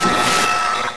citadel_bridgebeam1.wav